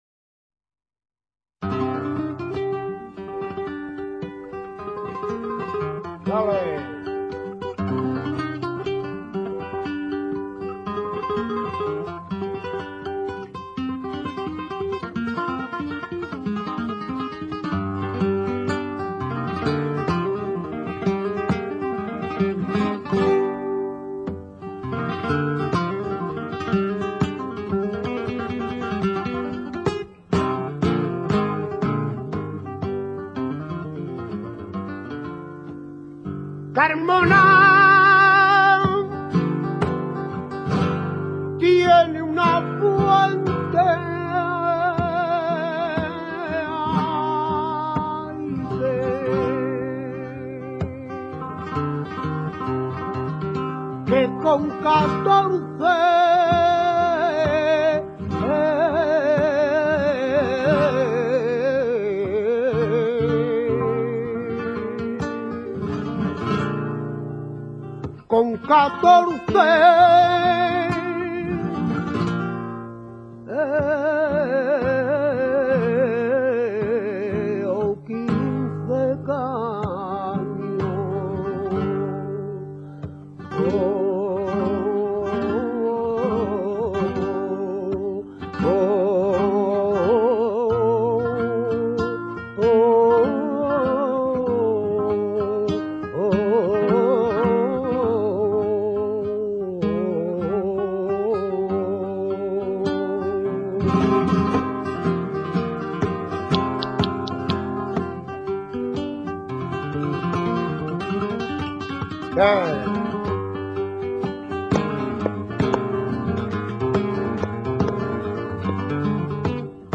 Sonidos y Palos del Flamenco
La modalidad de polo que actualmente se ejecuta es la llamada polo natural, que se inicia con un prolongado ayeo, que sirve de temple, y se suele rematar con una sole�.
polo.mp3